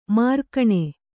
♪ mārkaṇe